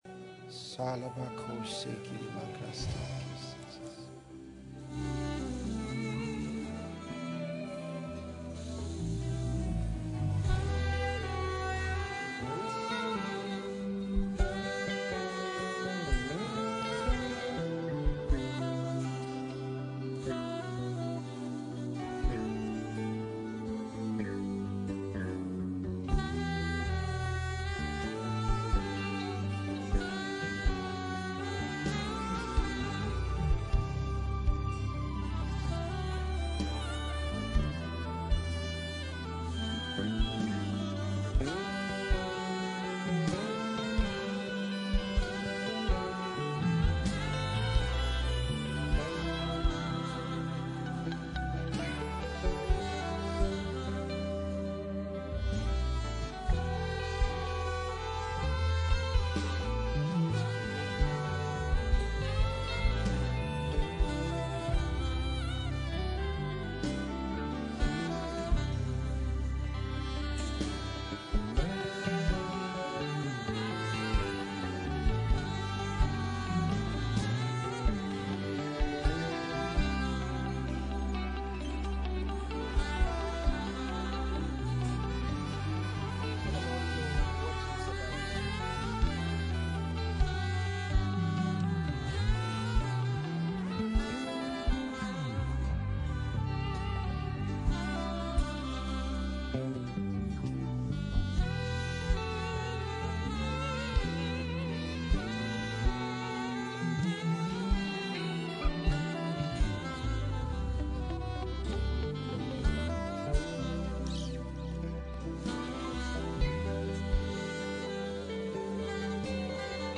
Enjoy this beautiful instrumental as you meditate or pray.